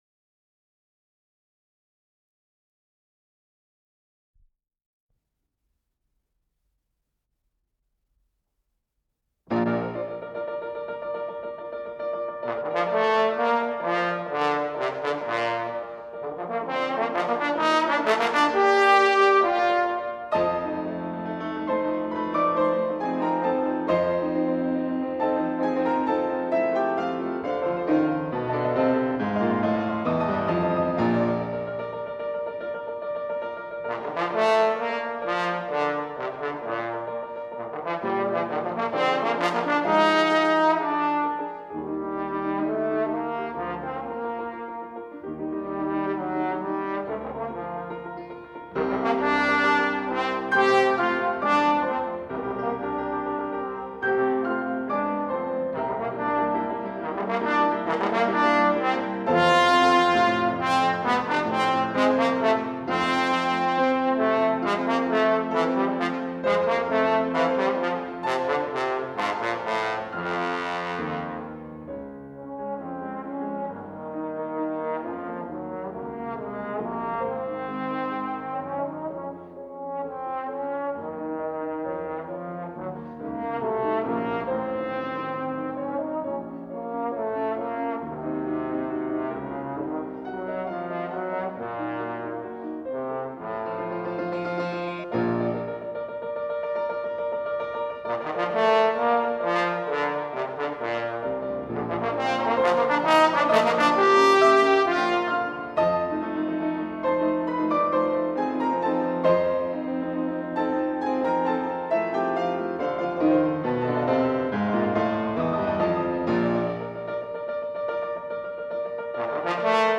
АВМ-005 — Концерт для тромбона и духового оркестра — Ретро-архив Аудио